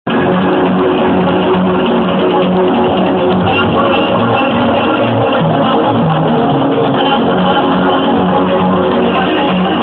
the quality is extremely bad!
It has some kind of male hymns (vocals) in it as well.